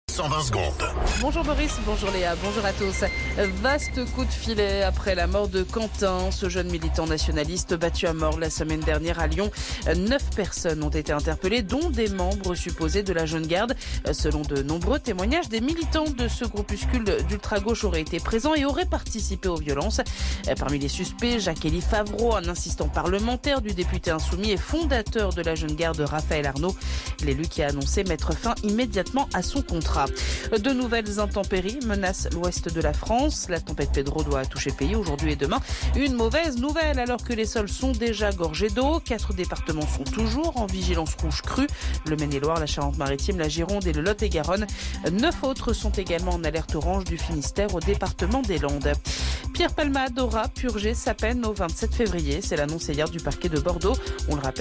Flash Info National 18 Février 2026 Du 18/02/2026 à 07h10 .